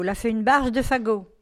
Localisation Saint-Mathurin
Catégorie Locution